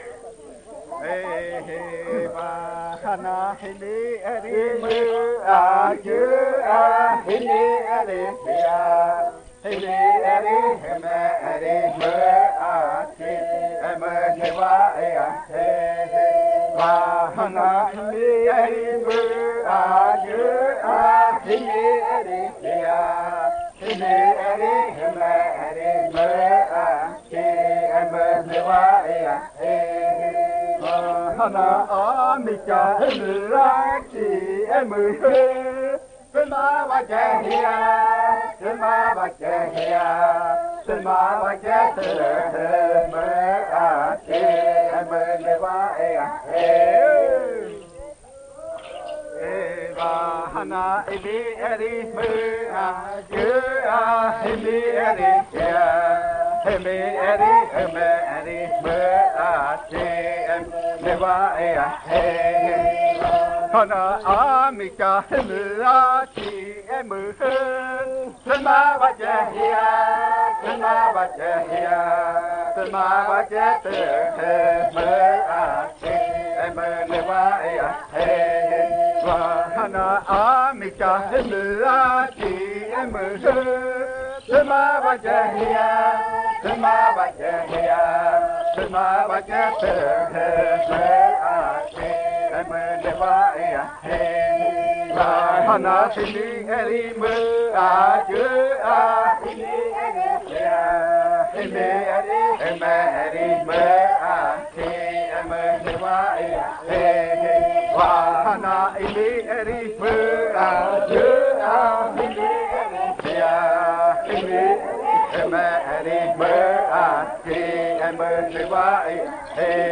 28. Baile de nombramiento. Canto n°20
Puerto Remanso del Tigre, departamento de Amazonas, Colombia